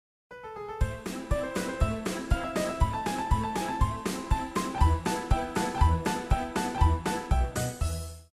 Circus Theme 2
circustheme2.mp3